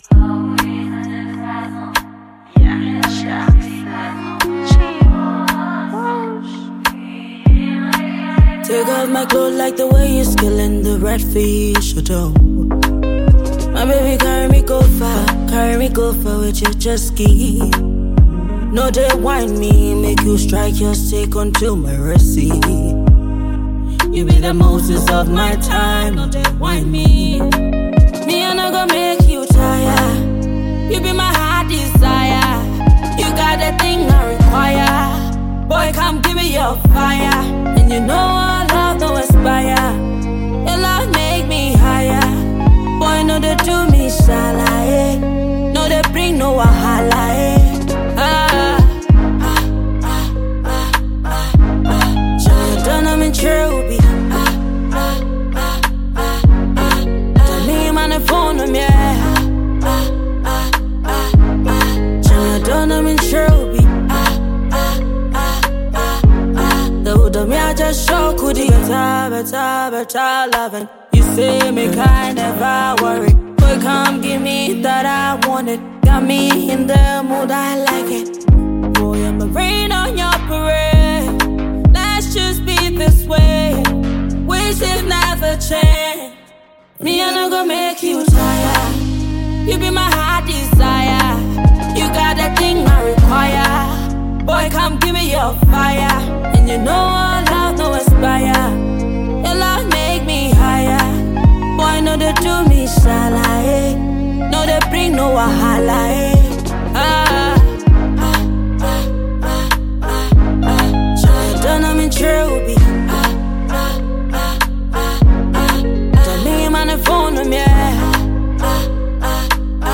vibrant new single